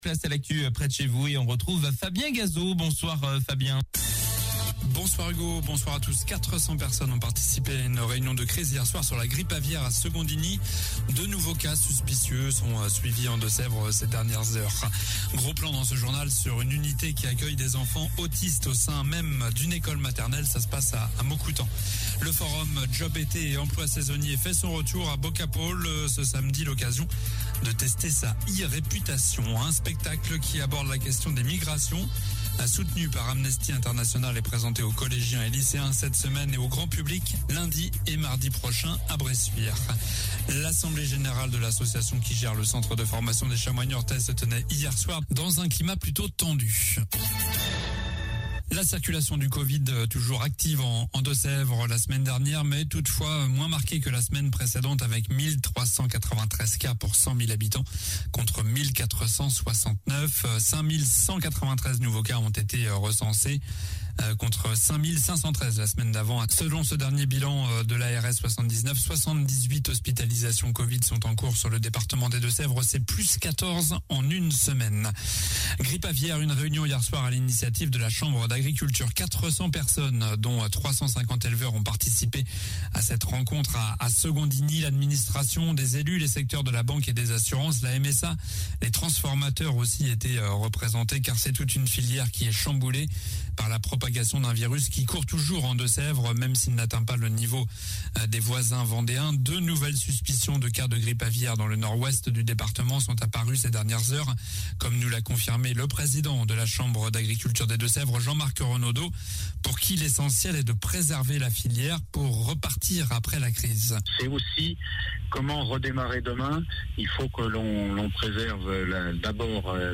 Journal du mardi 05 avril soir